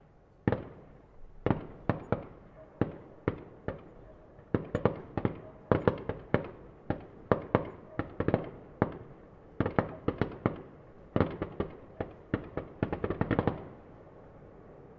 国内声音 " 焰火表演的爆炸声 2
描述：使用数字录像机创建并在Reaper中处理。
标签： 庆典 鞭炮 第5 帅哥 吊杆 火箭 烟花 烟花 爆竹 福克斯 火箭 烟火 爆炸 第四的七月 十一月
声道立体声